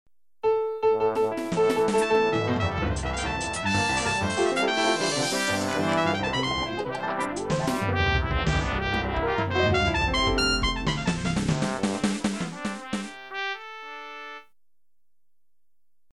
[影视音效][街头奏乐时的音效][剪辑素材][免费音效下载]-8M资料网